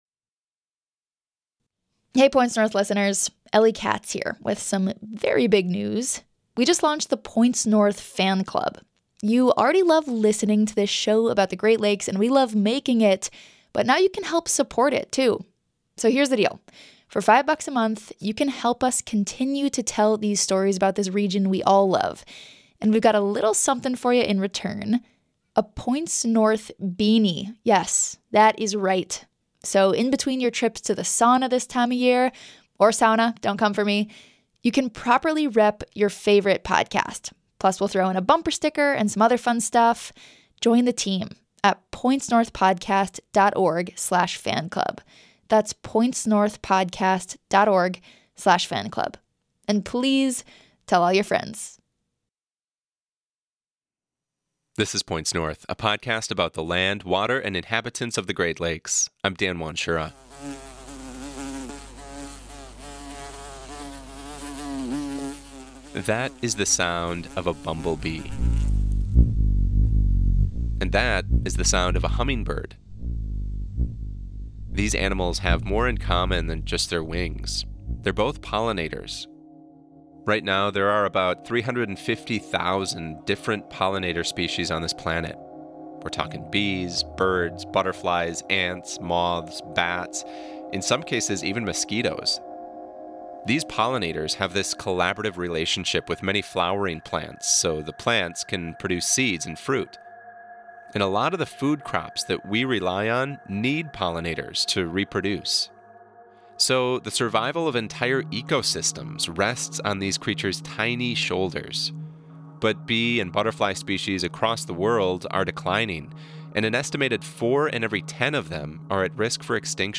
Points North is an award-winning podcast about the land, water, and inhabitants of the Great Lakes. Through narrative, sound-rich journalism that is deeply rooted in a sense of place, each episode entertains, informs, and surprises listeners everywhere.